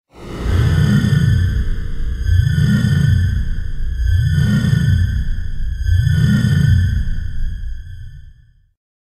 На этой странице собраны звуки голограмм — загадочные и футуристические аудиоэффекты, напоминающие технологии из научной фантастики.
Появилась голограмма